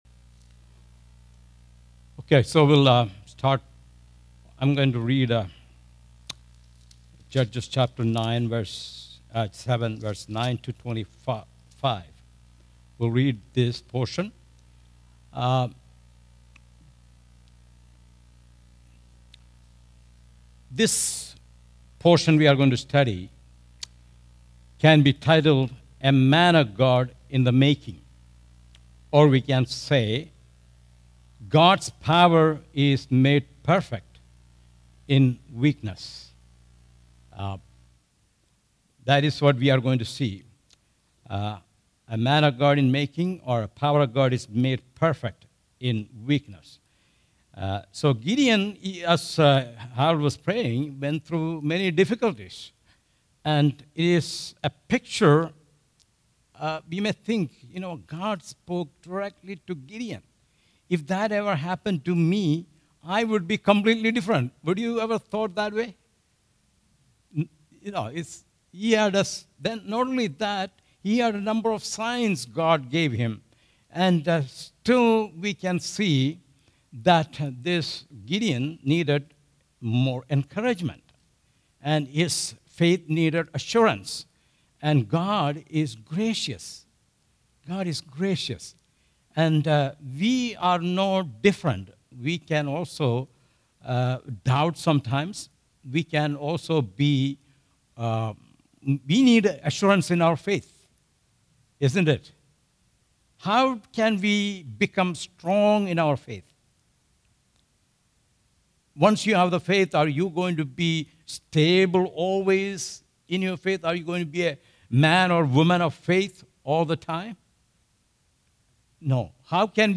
All Sermons Judges 7:9-25